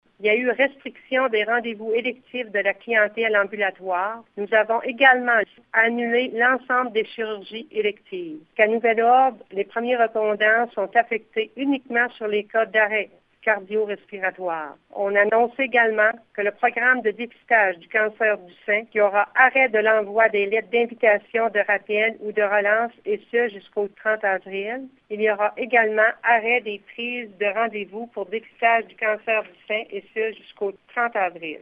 Lors du point de presse, les intervenants du CISSS de la Gaspésie ont confirmé qu’il n’y a toujours aucun cas de coronavirus  dans la région.